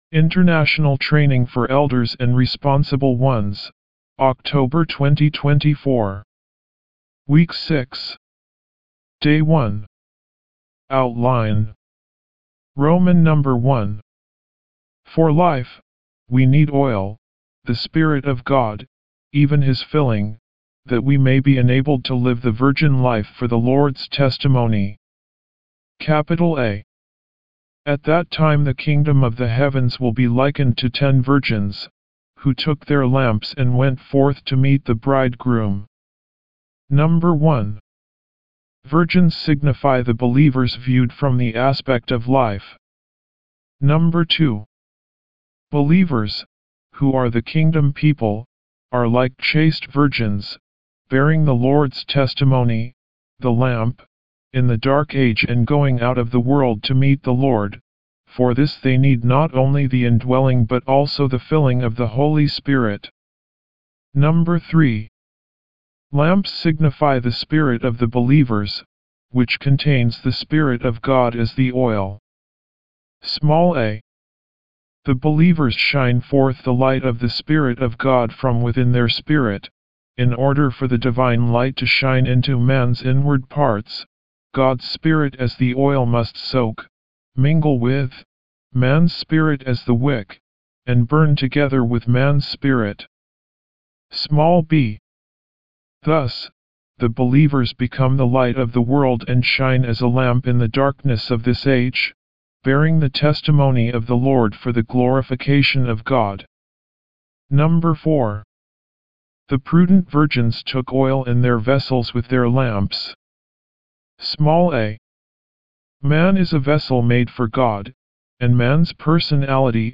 D1 English Rcite：